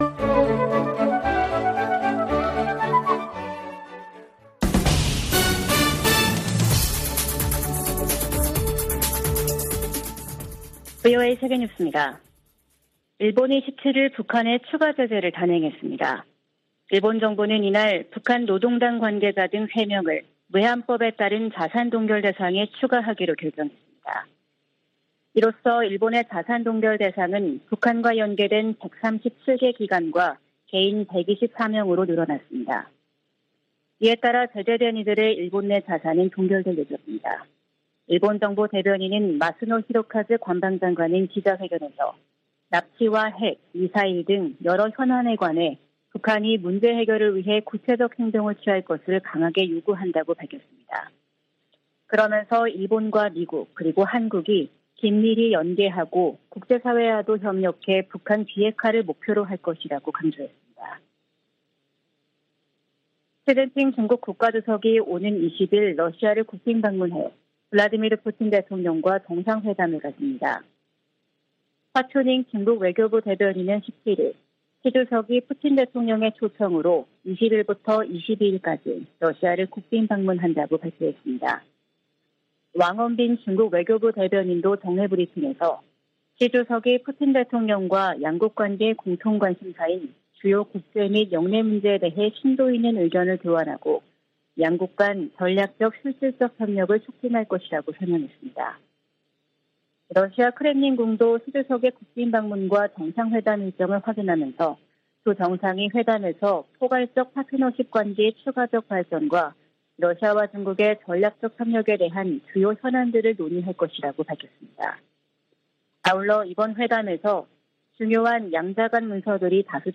VOA 한국어 아침 뉴스 프로그램 '워싱턴 뉴스 광장' 2023년 3월 18일 방송입니다. 백악관이 16일 열린 한일 정상회담에 적극적인 환영과 지지 입장을 밝혔습니다. 북한은 16일 대륙간탄도미사일 (ICBM) '화성-17형' 발사 훈련을 실시했다고 밝히고, 그 신뢰성이 검증됐다고 주장했습니다. 미 국방부는 북한의 지속적 도발에도 불구하고 대북 억제력이 작동하고 있다고 강조했습니다.